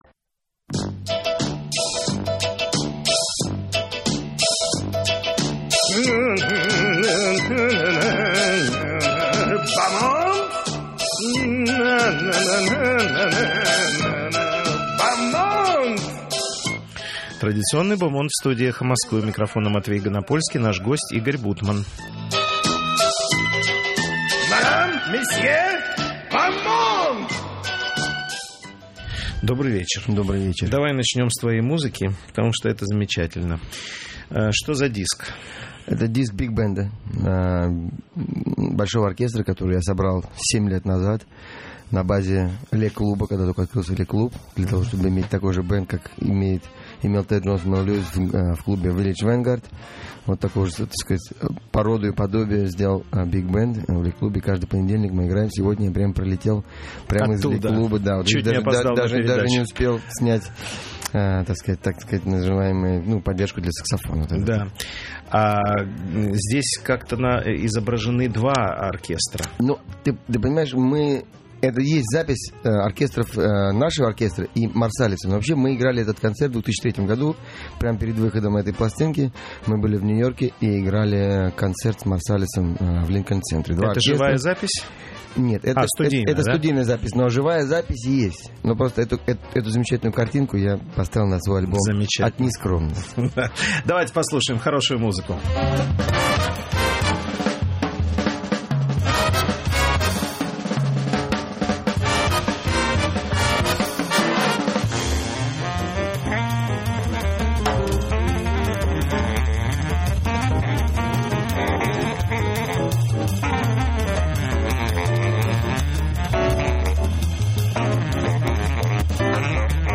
В эфире радиостанции «Эхо Москвы» - Игорь Бутман, джазовый музыкант, саксофонист.